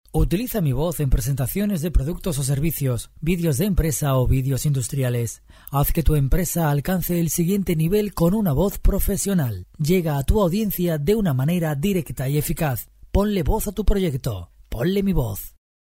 Locutor Profesional con más de 20 años de experiencia.
Sprechprobe: Industrie (Muttersprache):